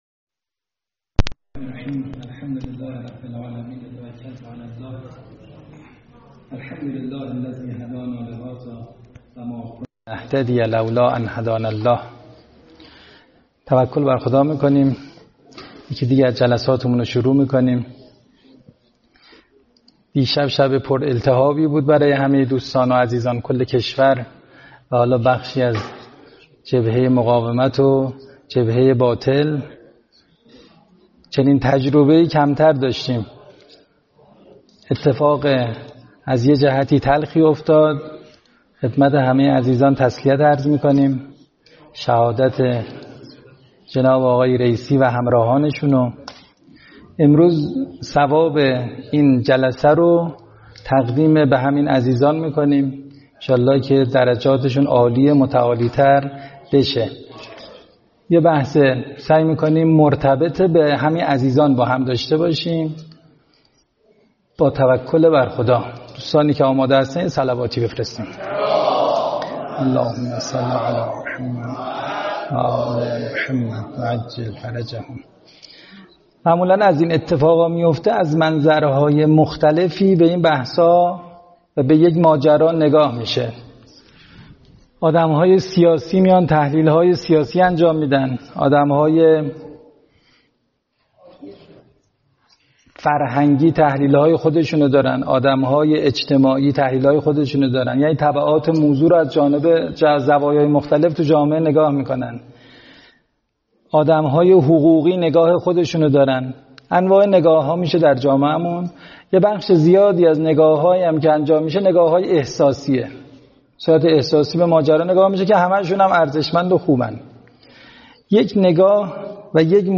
سخنرانی های